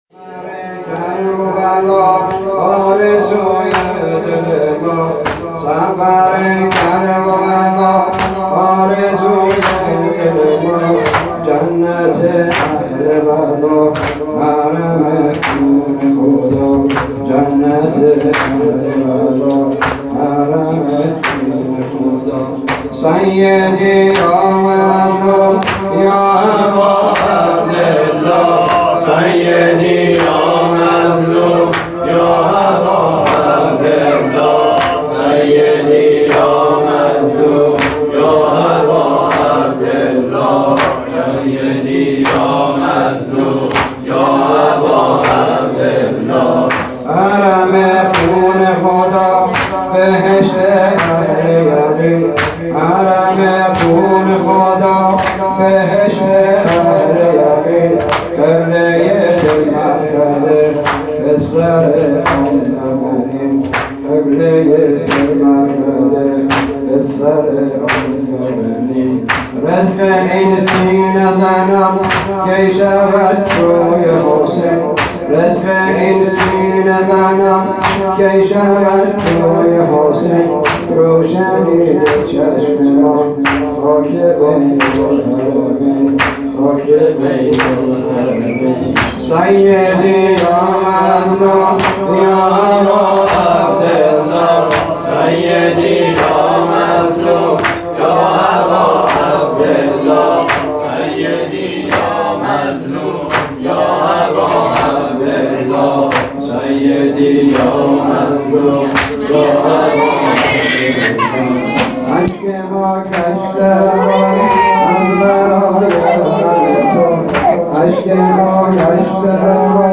هفتگی 5 مرداد _ شهادت امام جواد (ع)
نوحه شهادت حضرت جواد الائمه (ع) با نوای خادم الاهل البیت
عزاداری